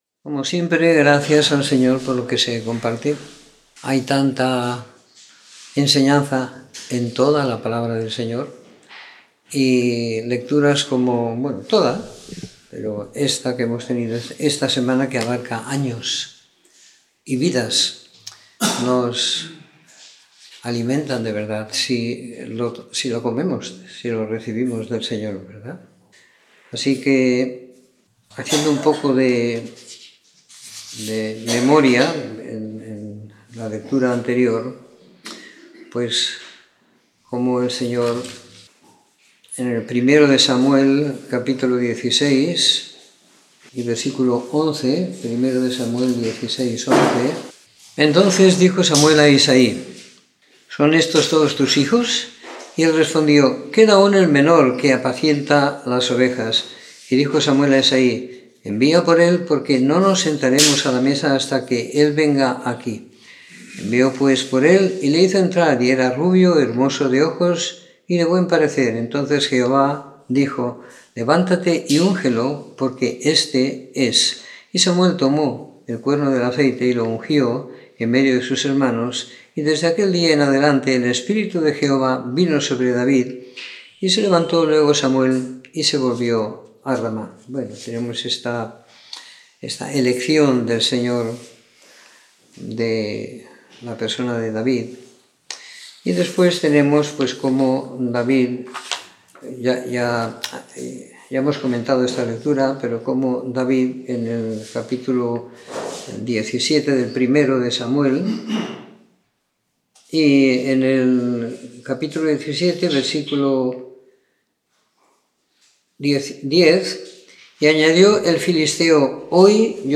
Comentario en 2º Samuel - 28 de Junio de 2019
Comentario en el segundo libro de Samuel siguiendo la lectura programada para cada semana del año que tenemos en la congregación en Sant Pere de Ribes.